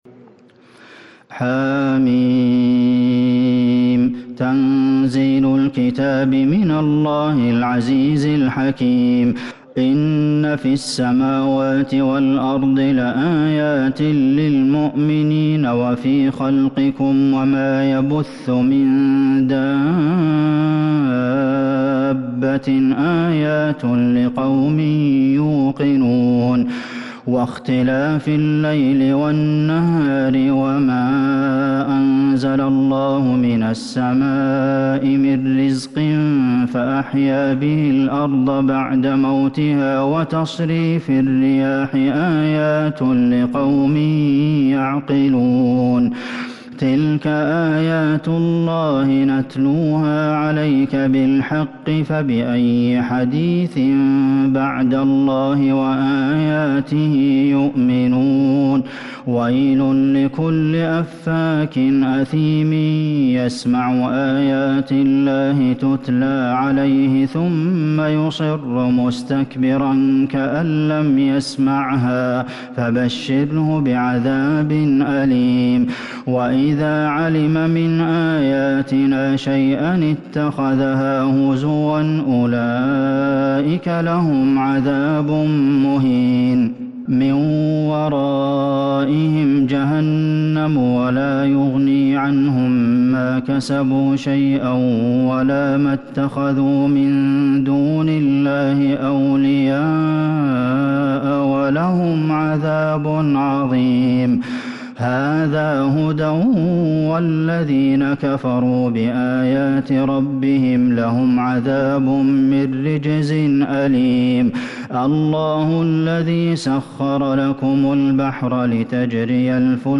سورة الجاثية Surat Al-Jathiyah من تراويح المسجد النبوي 1442هـ > مصحف تراويح الحرم النبوي عام ١٤٤٢ > المصحف - تلاوات الحرمين